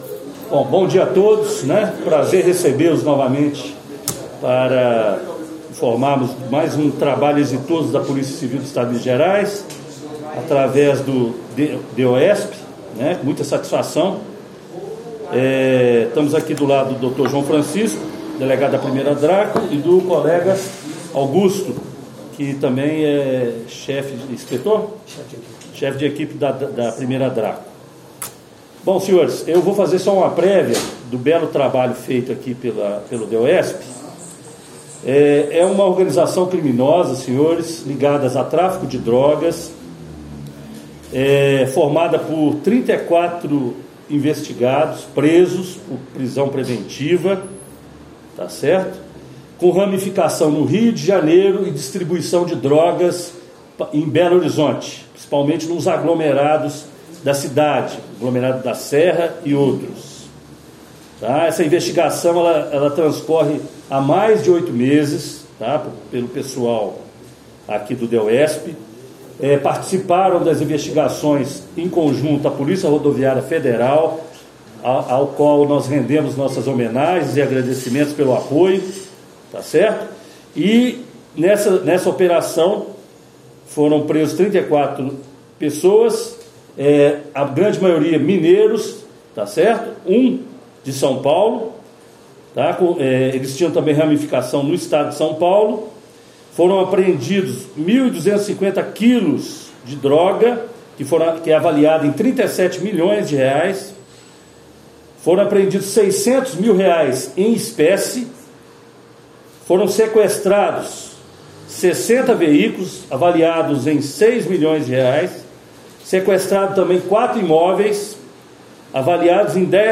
Coletiva.mp3